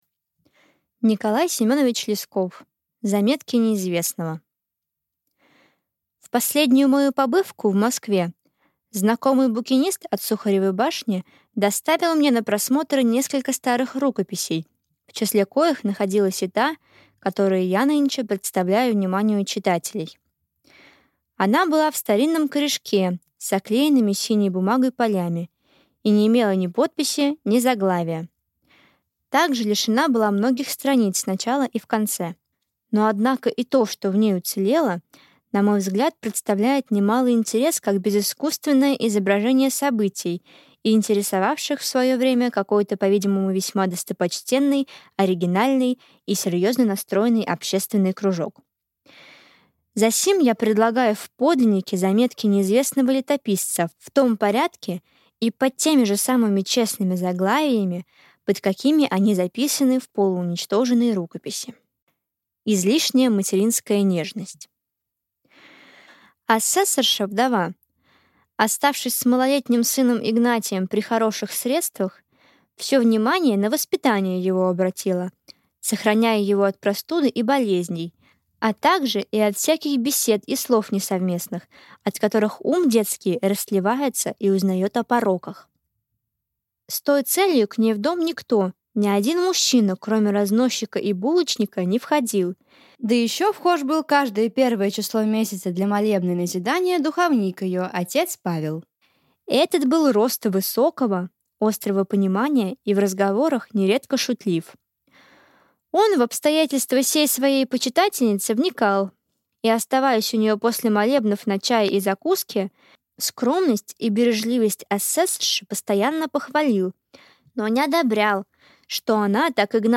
Аудиокнига Заметки неизвестного | Библиотека аудиокниг